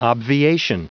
Prononciation du mot obviation en anglais (fichier audio)
Prononciation du mot : obviation